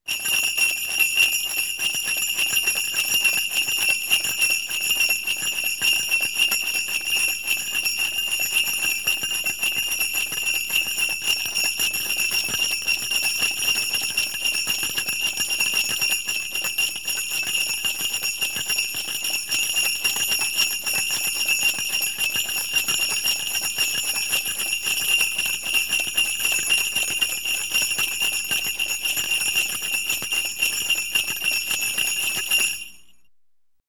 Sleigh Bells 2 Sound Effect Free Download
Sleigh Bells 2